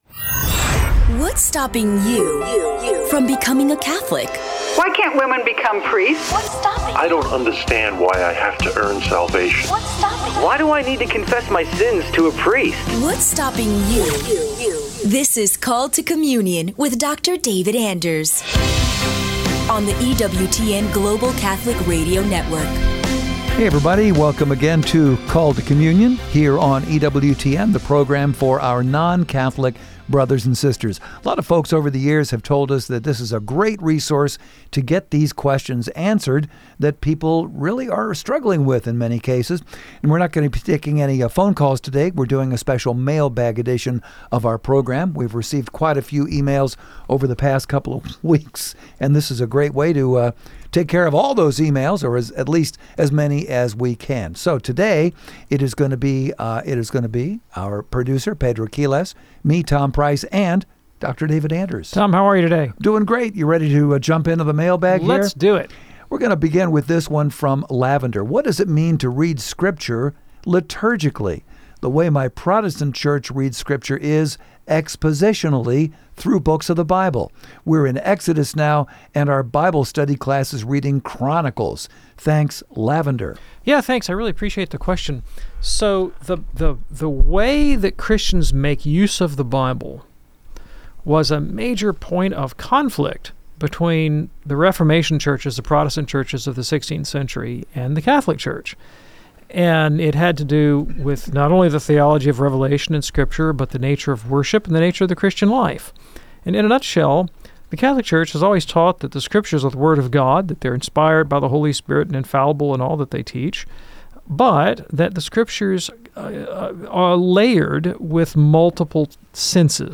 public ios_share Called to Communion chevron_right Tongues and the Baptism in the Spirit Mar 24, 2026 A mailbag-style conversation about reading Scripture liturgically versus expository preaching.